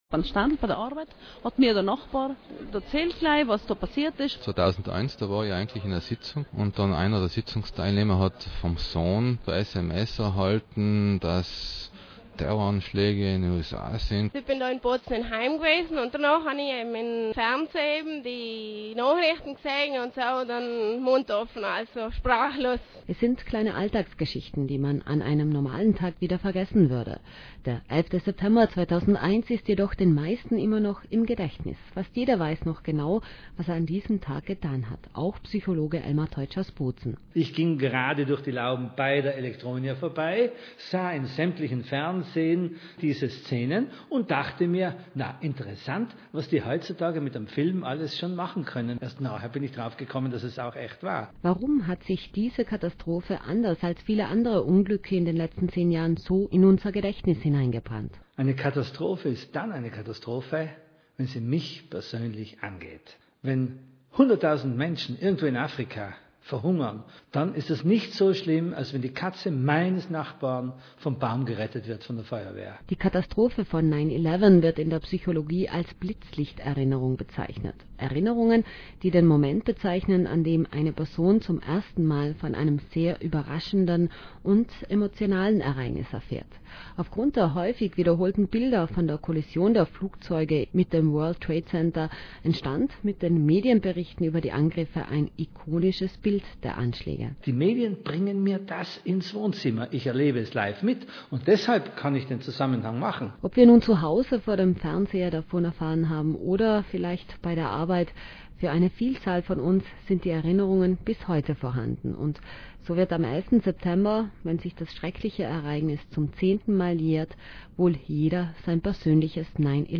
Radio-Interview: Gratis-Download im komprimierten mp3-Format (373 KB) >>
RS1-WTC-Erinnerungen.mp3